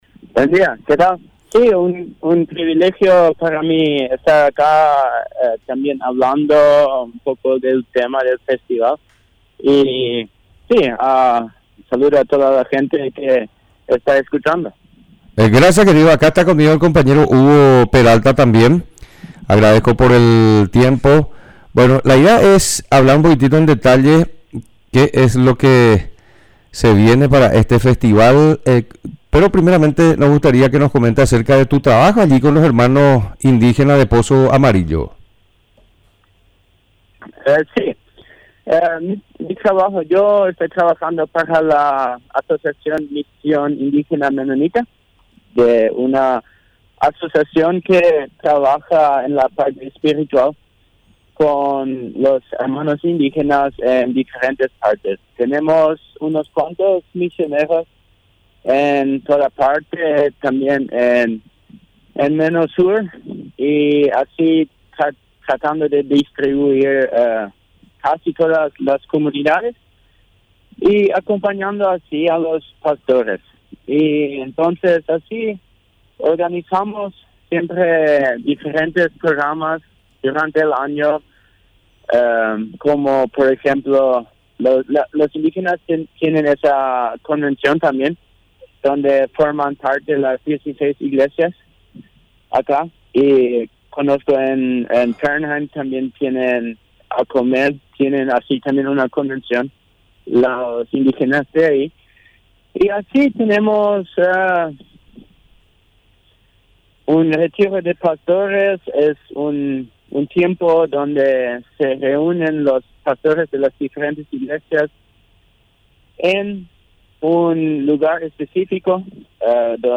Entrevistas / Matinal 610 Festival de esperanza en pozo amarillo Oct 31 2024 | 00:12:40 1x 00:00 / 00:12:40 Subscribe Share RSS Feed Share Link Embed